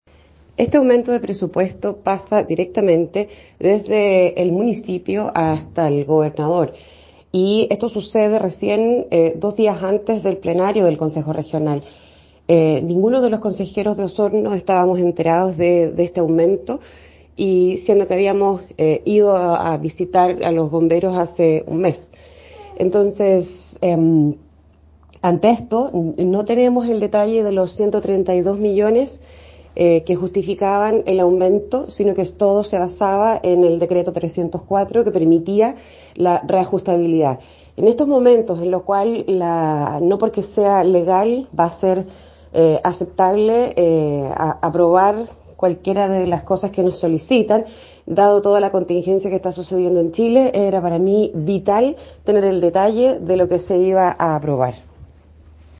La consejera regional y vice presidenta de la comisión de fomento productivo del Core, Andrea Iturriaga, siempre ha apoyado a bomberos en su calidad de consejera; sin embargo, se abstuvo de votar al no tener el detalle acabado de la solicitud de los 132 millones que presentó la I. Municipalidad de Osorno.